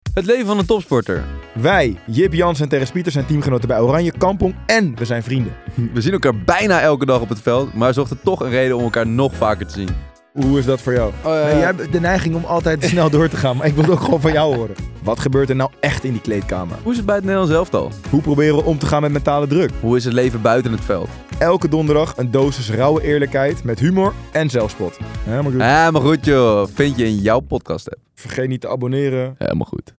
Helemaal-Goed-Joh-PREROLL.mp3